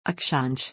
Pronunciation
əkʂɑːⁿɕ: Chunking it down into simple syllables, it’s pronounced roughly like UCK (as in Duck) - SHAAN - SH.